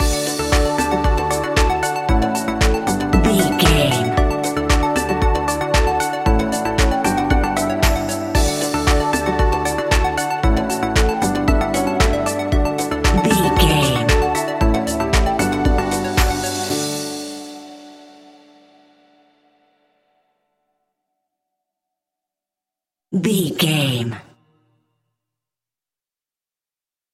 Ionian/Major
D
groovy
energetic
uplifting
hypnotic
drum machine
synthesiser
piano
house
electro house
funky house
synth leads
synth bass